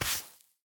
Minecraft Version Minecraft Version snapshot Latest Release | Latest Snapshot snapshot / assets / minecraft / sounds / item / brush / brushing_gravel3.ogg Compare With Compare With Latest Release | Latest Snapshot
brushing_gravel3.ogg